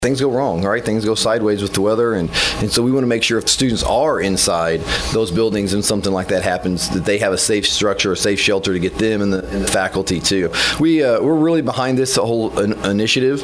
Farmington City Councilman, and a patron of the district, Chad Follis, says students need the safety of the shelter and after this past weekend's severe weather, it's needed even more.